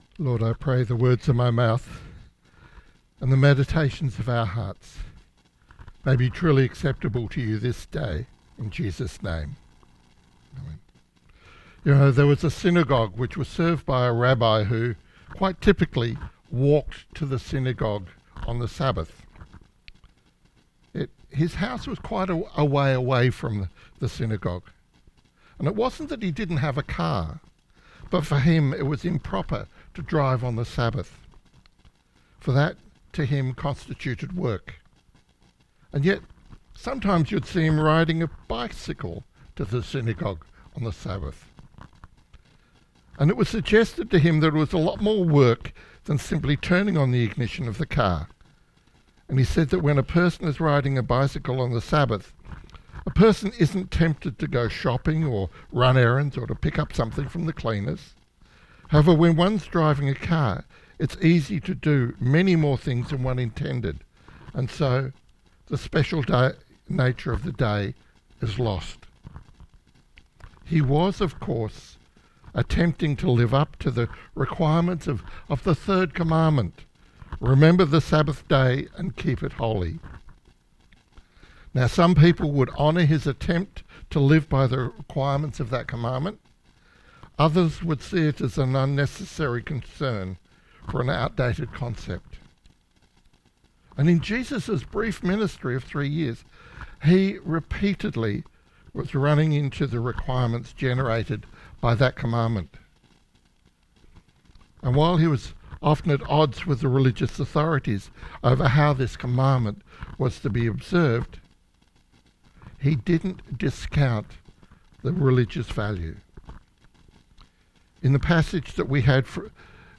Sermon 2nd June – A Lighthouse to the community